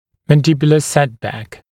[ˌmæn’dɪbjulə ‘setbæk][ˌмэн’дибйулэ ‘сэтбэк]сдвиг нижней челюсти назад